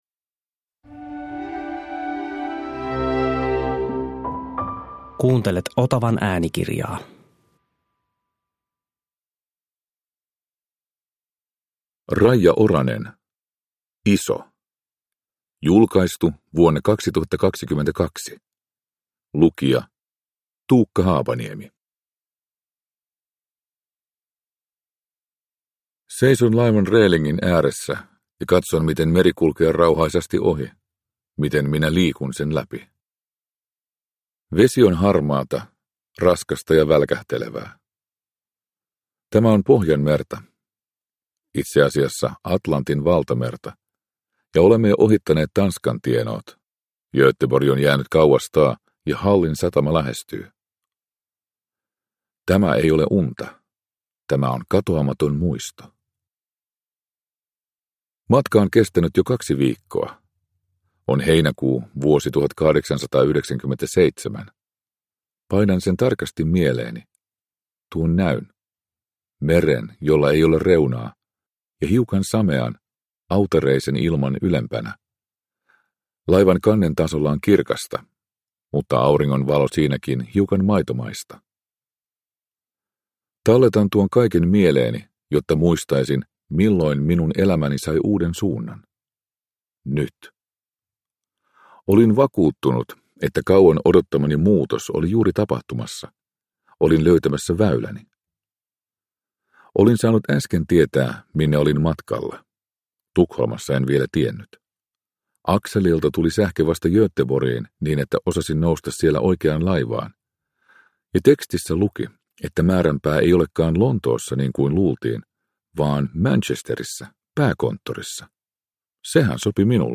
Iso – Ljudbok – Laddas ner